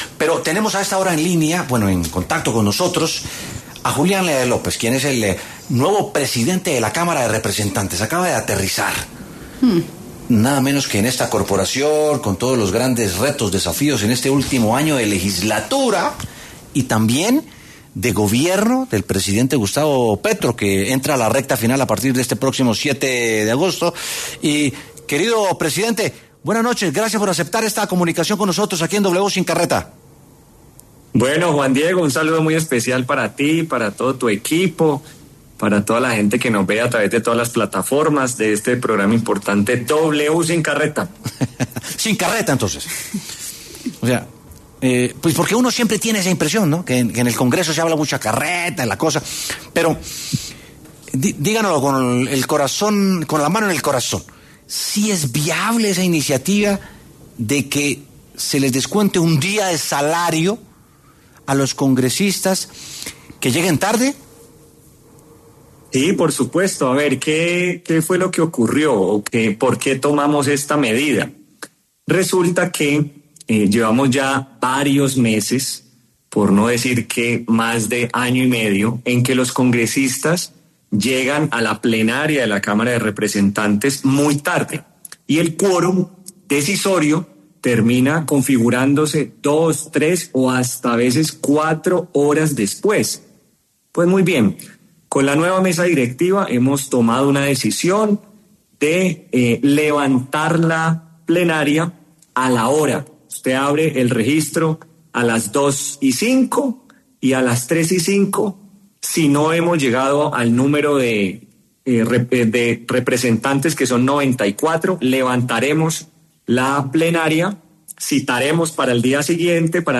Este martes, 5 de agosto, el presidente de la Cámara de Representantes, Julián López, pasó por los micrófonos de W Sin Carreta y habló de la iniciativa tomada ante la impuntualidad de algunos congresistas.